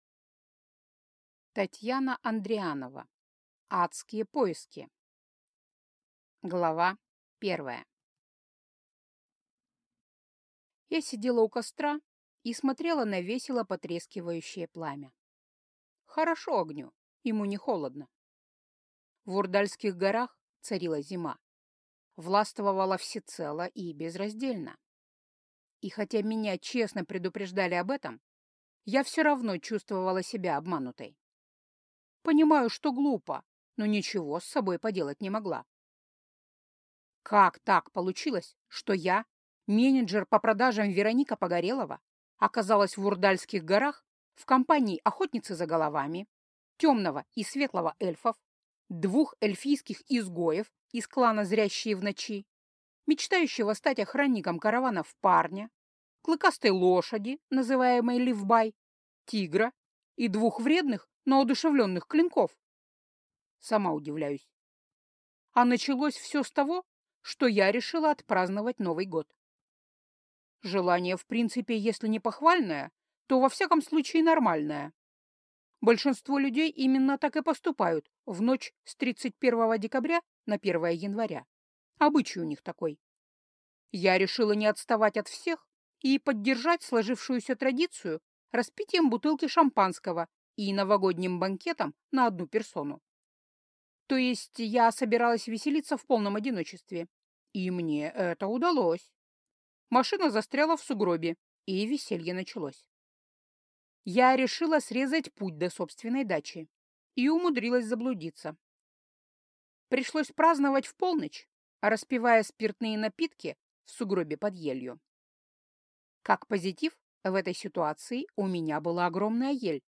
Аудиокнига Адские поиски | Библиотека аудиокниг